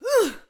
SFX_Battle_Vesna_Attack_03.wav